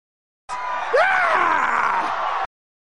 DEAN SCREAM